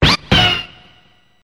One of Yoshi's voice clips in Mario Party 2